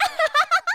File:Zelda voice sample SSBU.oga
Zelda_voice_sample_SSBU.oga.mp3